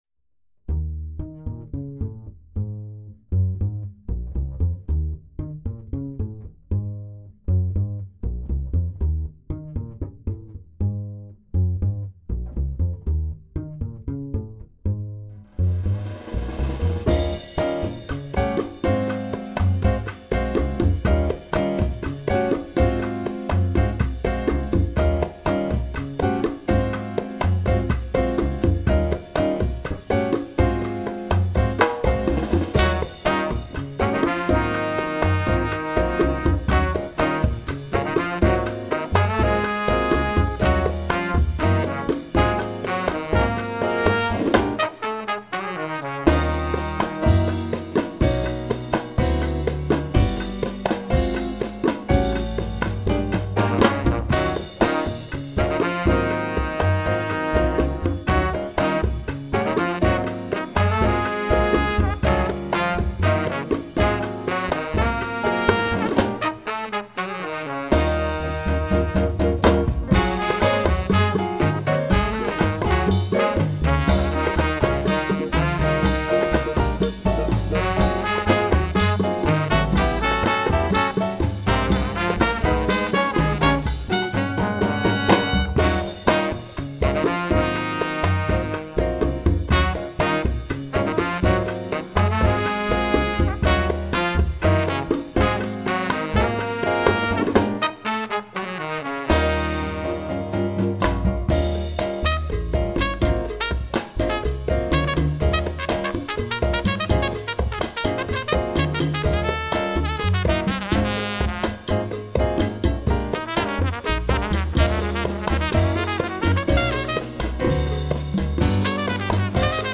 Latin jazz
signature high-energy sound
timbales
piano
congas